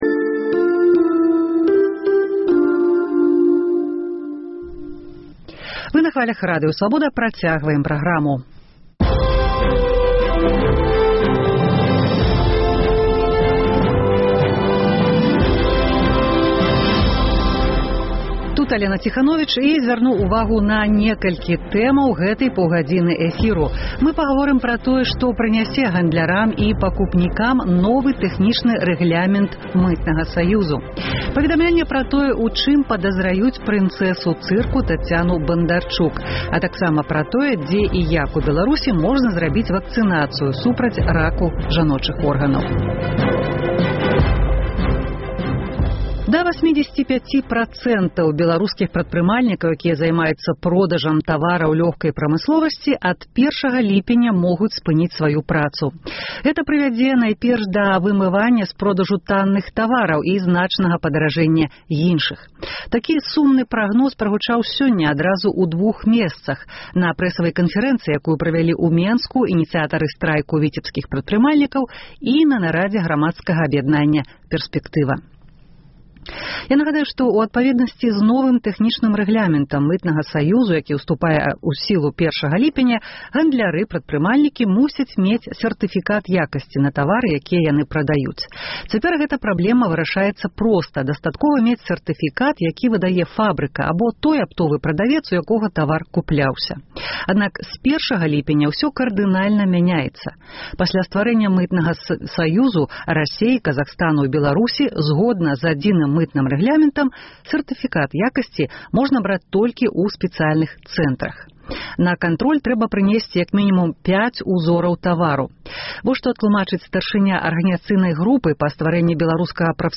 Рэпартаж з вёскі Мардзьвін Петрыкаўскага раёну. Беларускія аспэкты амэрыканскага скандалу з сачэньнем і праслухоўваньнем. Дзе і як ў Беларусі можна зрабіць вакцынацыю супраць раку жаночых органаў?